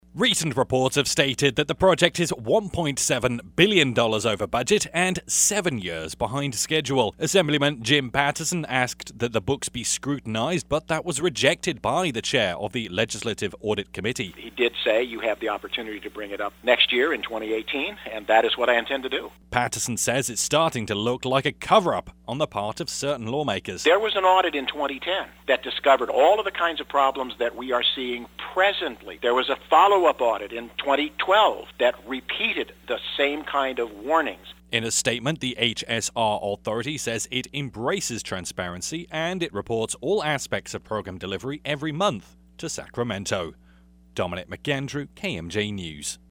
as it aired: